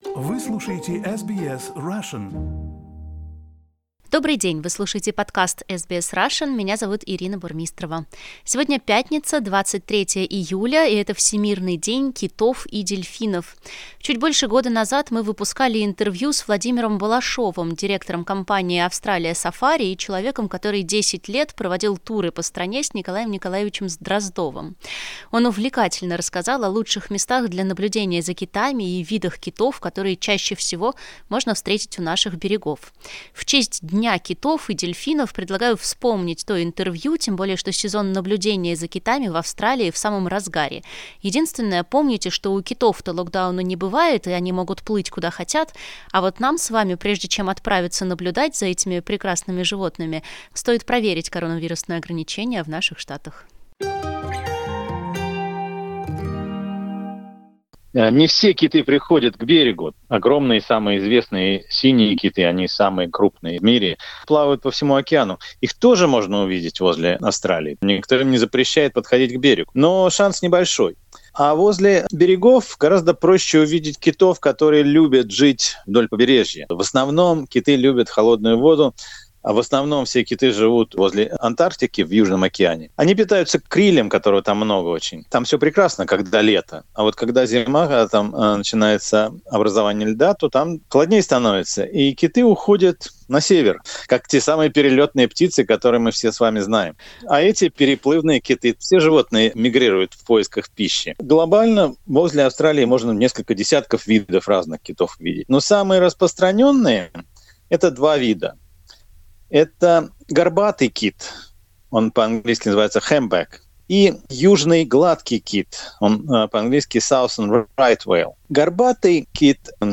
Чуть больше года назад мы выпускали интервью о китах